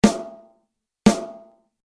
Drumset-Mikrofonierung
Die Kapsel zeigt mit der Haupteinsprechrichtung in dieser Position leicht schräg auf einen Punkt, der etwas außerhalb der Mitte der Snaredrum liegt. Der Anschlag wird so recht deutlich aufgezeichnet.